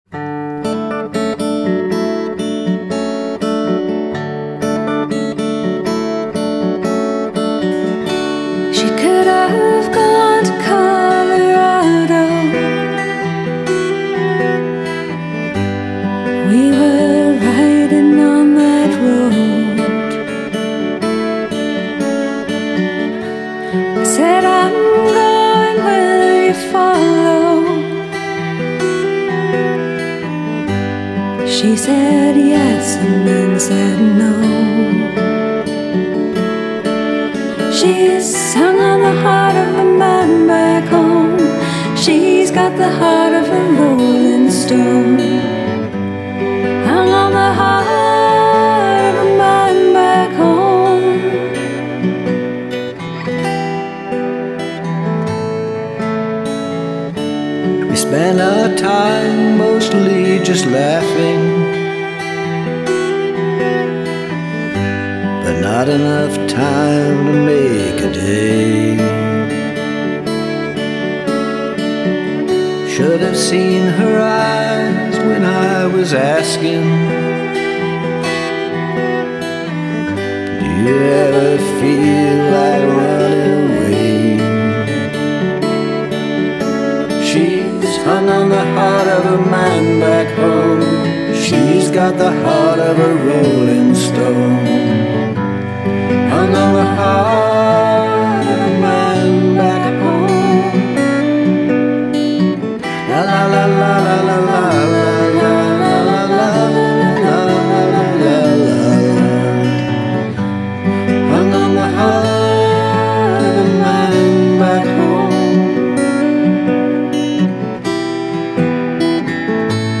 So many people joined in on the chorus on these songs.